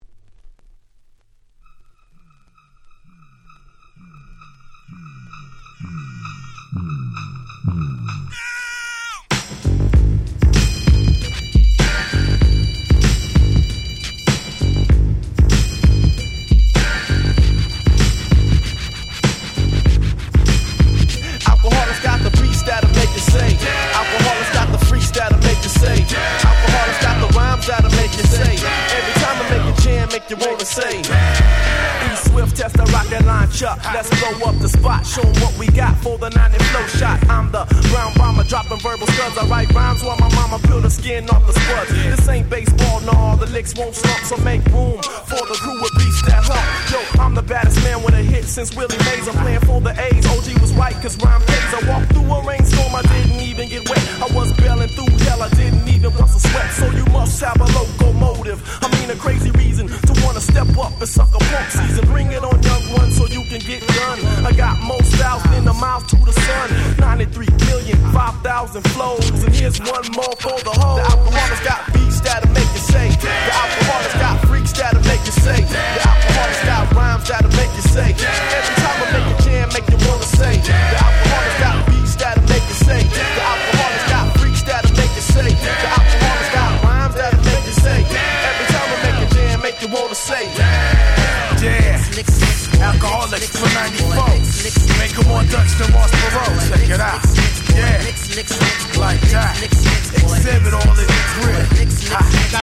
94' Smash Hit Hip Hop !!
彼らってLAのグループながらG-Funkやらウエッサイよりに傾かない珍しいグループですよね！
90's Boom Bap ブーンバップ